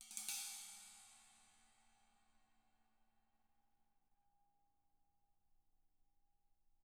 Index of /90_sSampleCDs/ILIO - Double Platinum Drums 2/Partition H/CYMBALRUFFSD